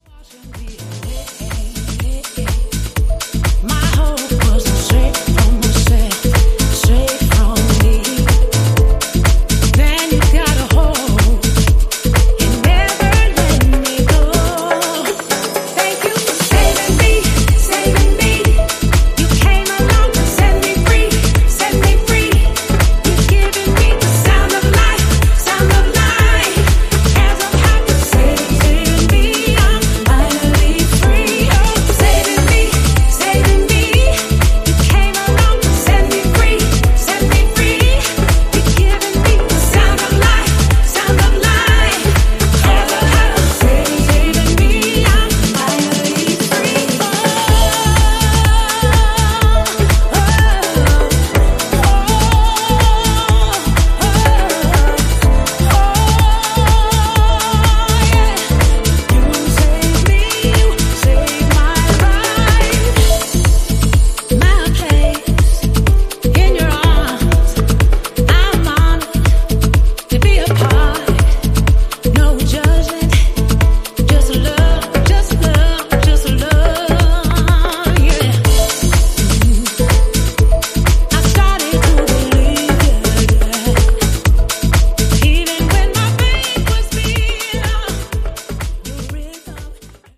ジャンル(スタイル) SOULFUL HOUSE / DEEP HOUSE